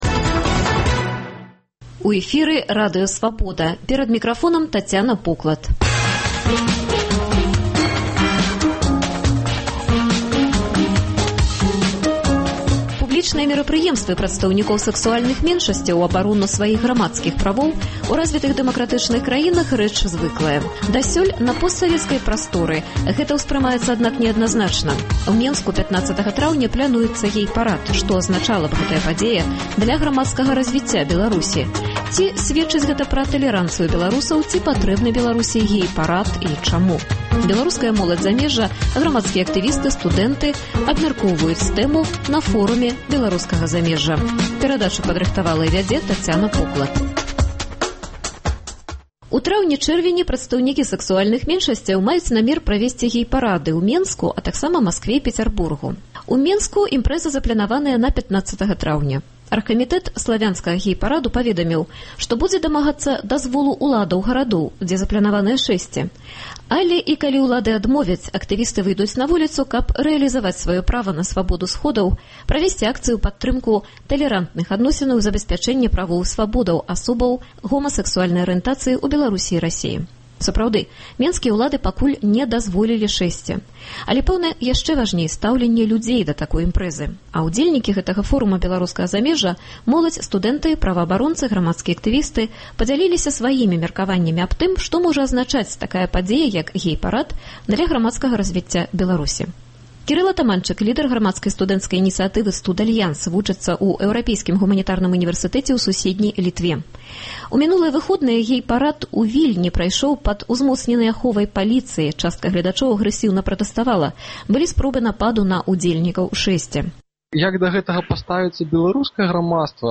Ці патрэбны Беларусі гей-парад і чаму? Беларуская моладзь замежжа, – сваімі меркаваньнямі дзеляцца маладыя беларусы з Аўстраліі, ЗША і краін Эўразьвязу.